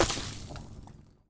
poly_explosion_lava.wav